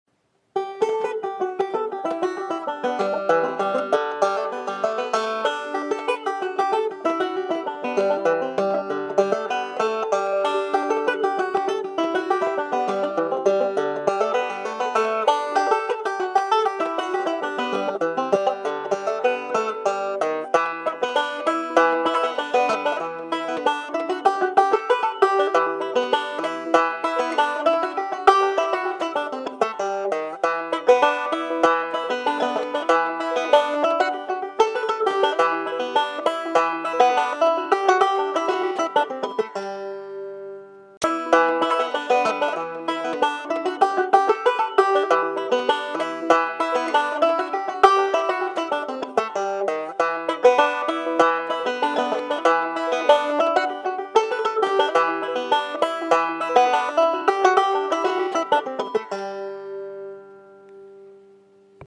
Key: G
Form: Reel or Hornpipe (Bluegrass)
Source: Trad.
Region: USA